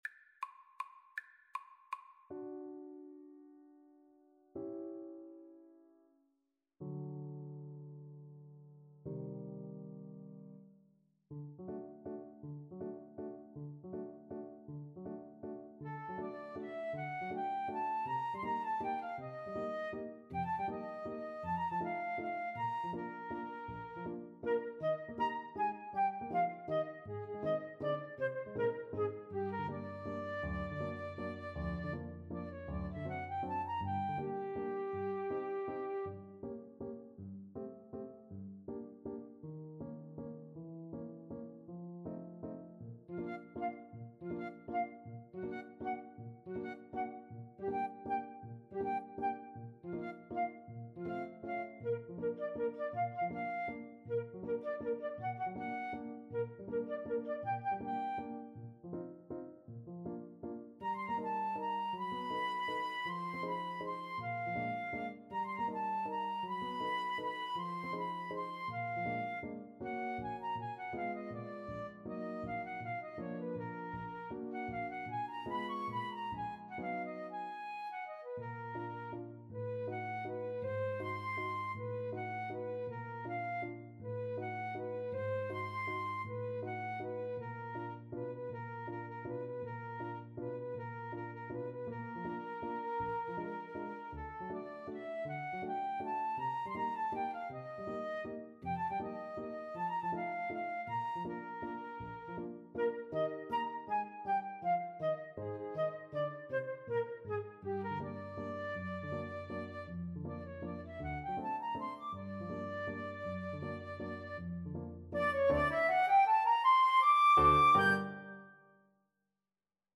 FluteClarinetPiano
3/4 (View more 3/4 Music)
Allegretto = 160
Classical (View more Classical Flute-Clarinet Duet Music)